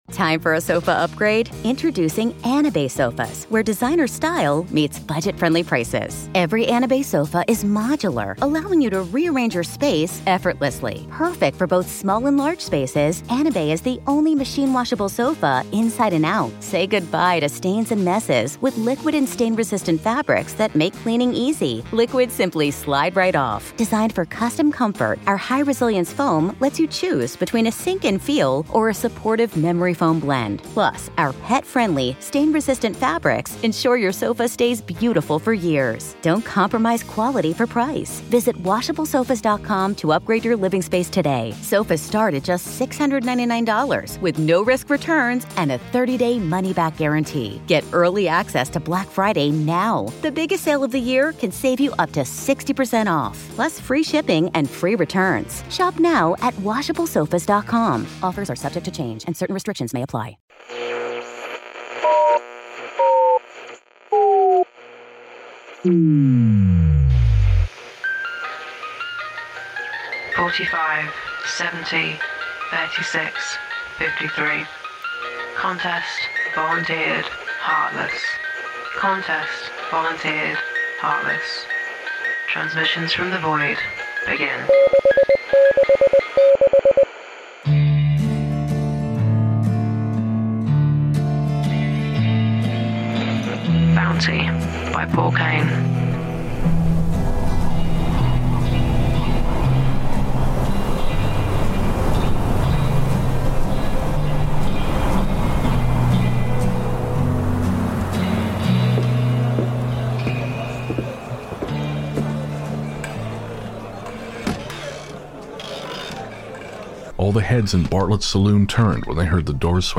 Bounty: A Ghost Story Guys Audio Drama